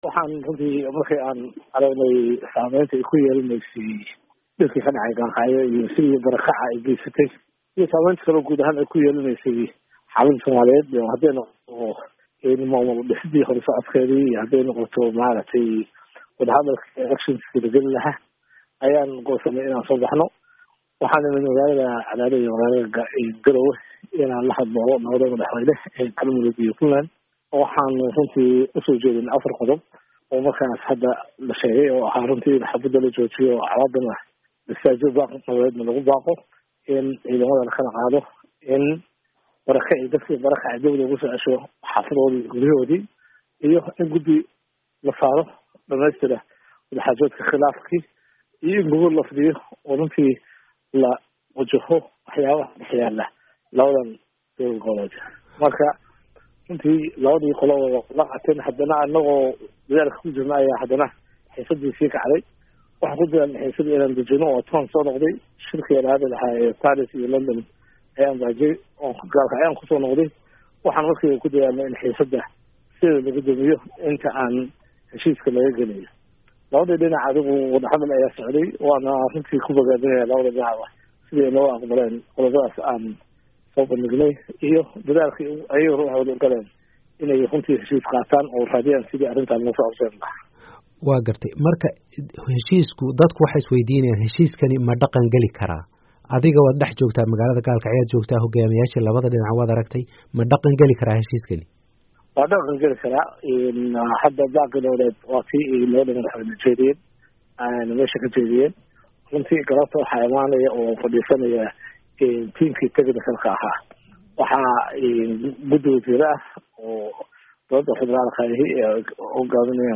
Dhagayso: Raysul Wasaare Sharmarke oo ka hadlaya isku keenidii madaxweyne Gaas & C/kariim